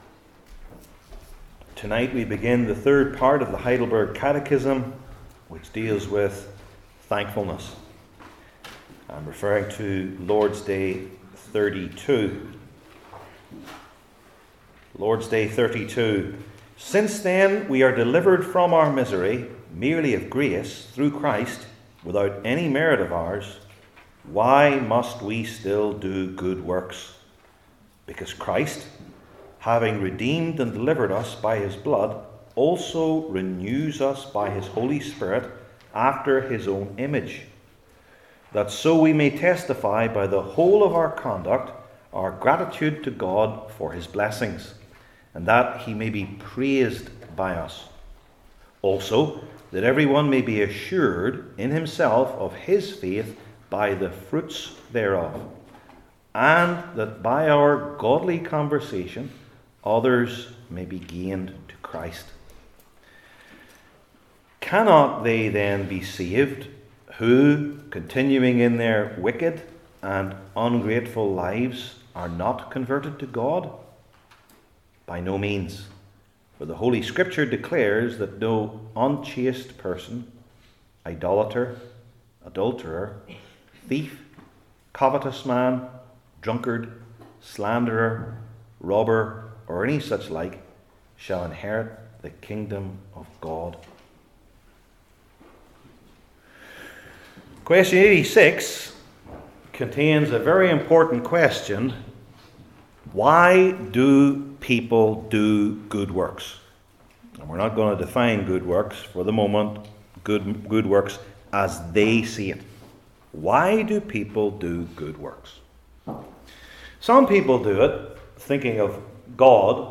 Heidelberg Catechism Sermons I. By Whom?